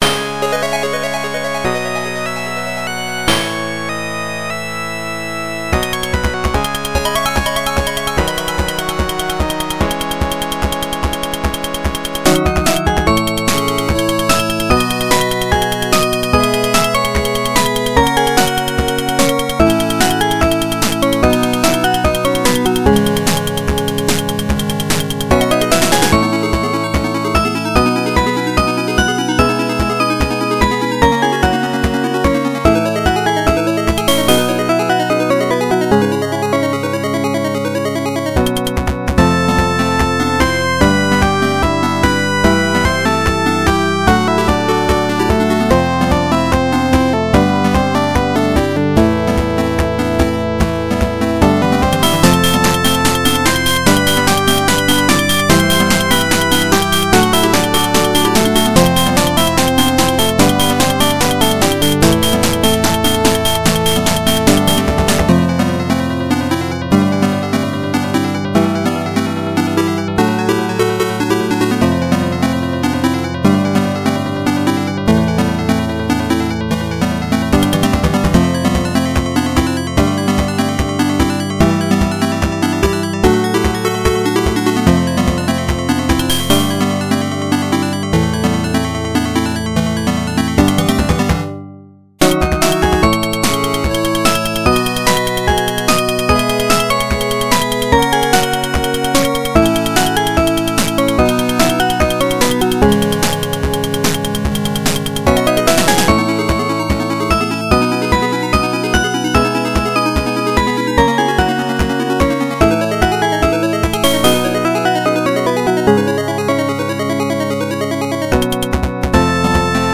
原游戏FM26K版，由PMDPlay导出。